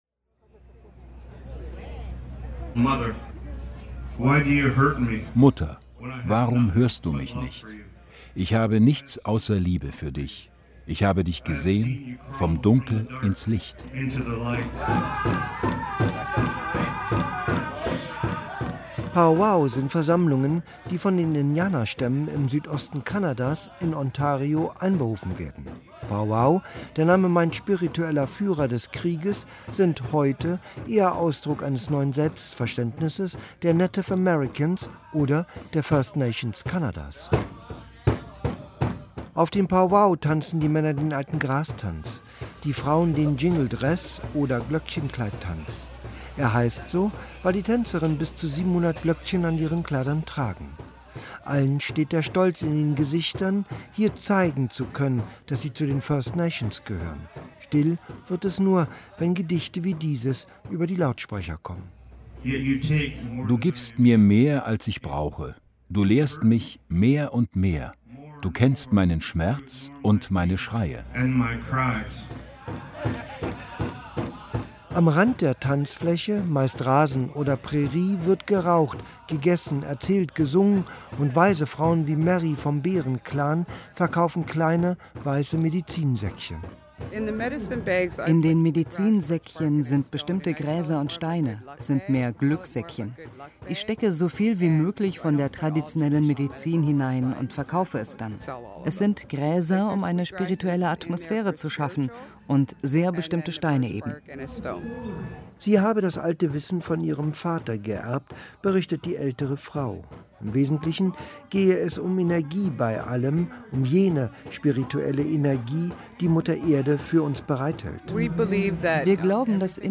As a result of the recent ‘Indigenous Toronto’ press trip hosted by Tourism Toronto, WDR 5 featured “Indigenous Spirituality in Canada” during a broadcast July, 22.
The feature is supplemented by traditional music recorded at Pow Wow.